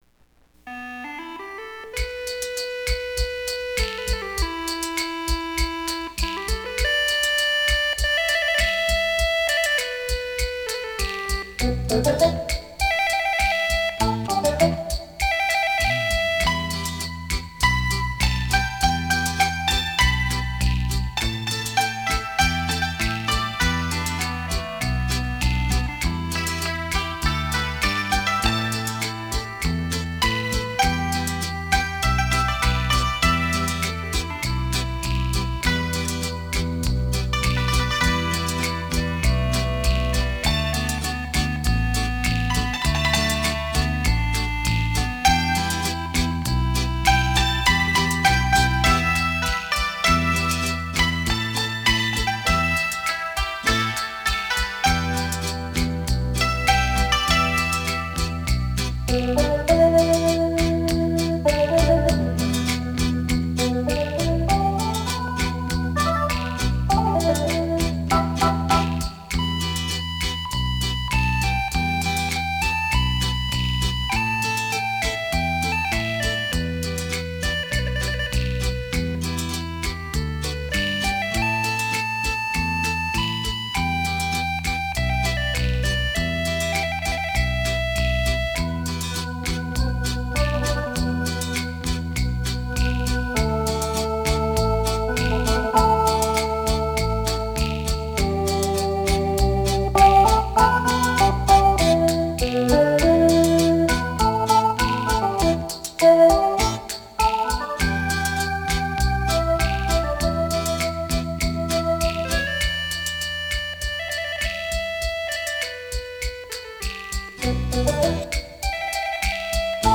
扬琴是完全的中国情怀 幽雅飘扬的中国诗意